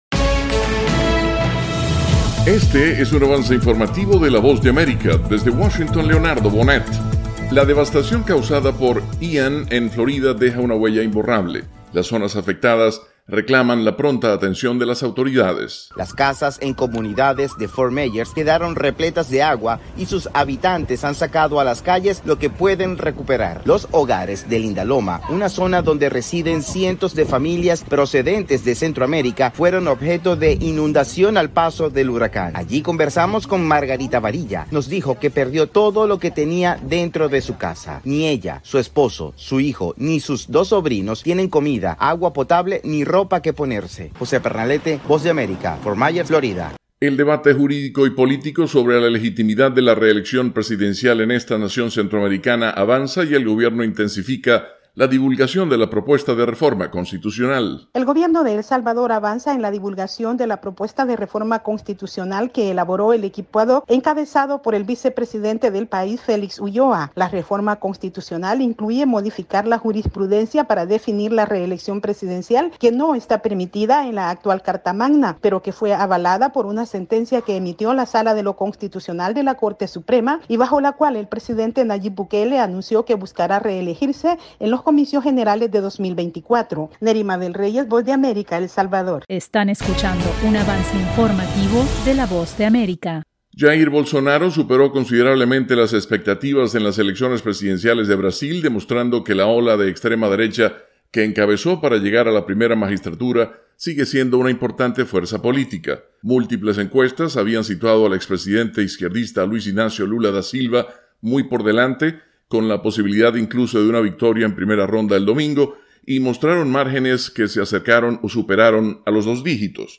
El siguiente es un avance informativo presentado por la Voz de América, desde Washington,